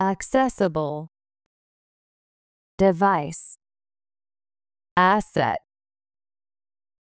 accessible /əkˈsɛsəbl/（形）アクセス可能な、利用可能な
device /dɪˈvaɪs/（名）装置、機器
asset /ˈæsɛt/（名）資産、財産